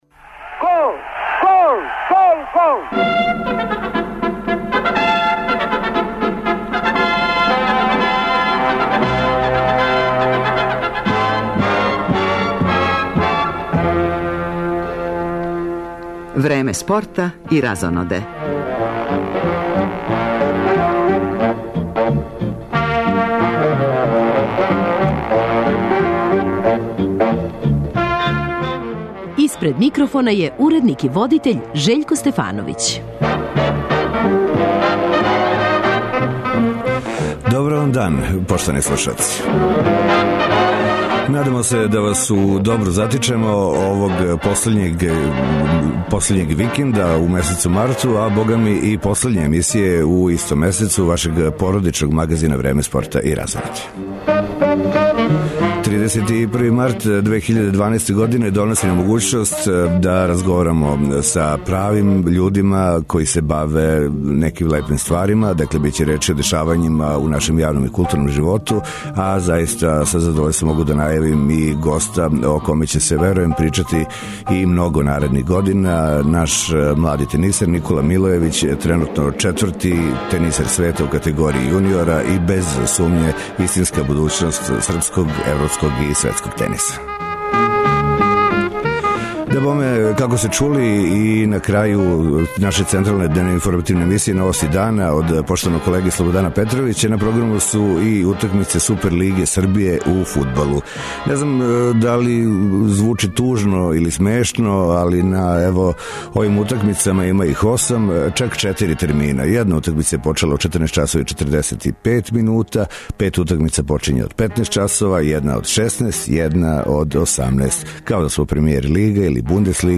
Редовно коло првенства Супер лиге Србије у фудбалу игра се у више термина, па ће се репортери јављати током целе емисије са стадиона широм Србије.